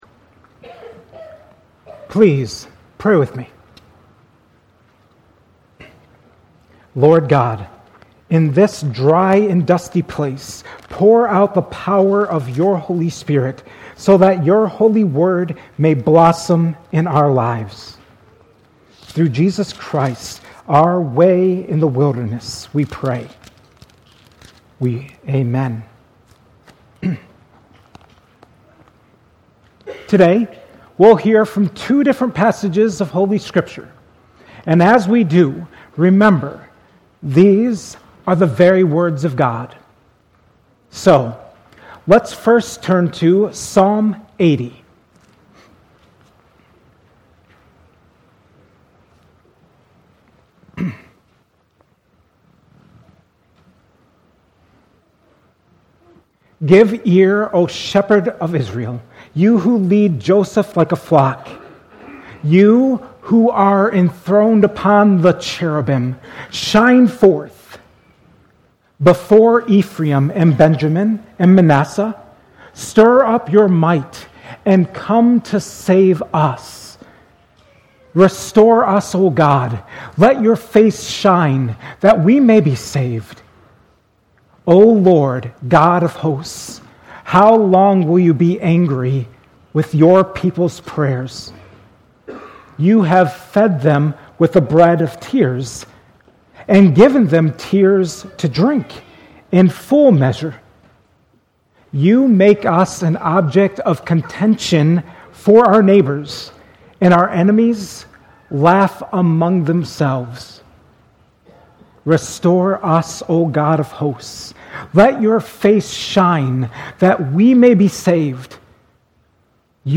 2025 at Cornerstone Church in Pella.